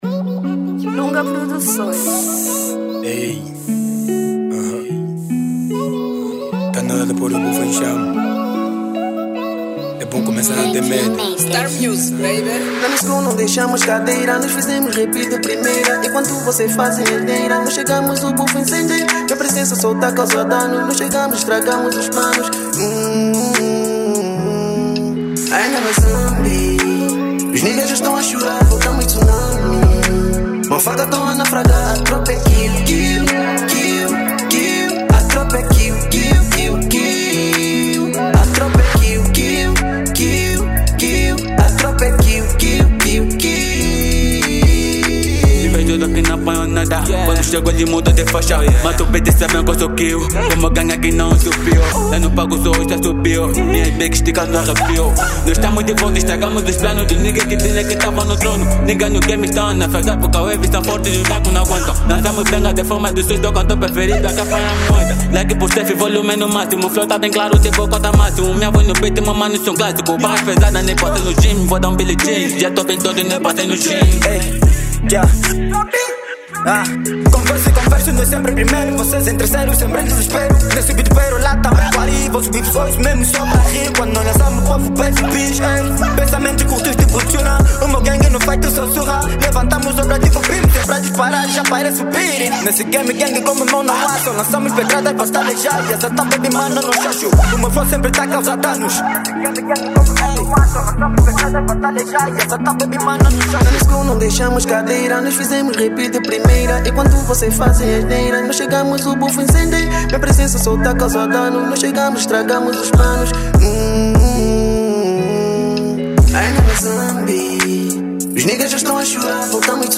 Estilo: Trap Drill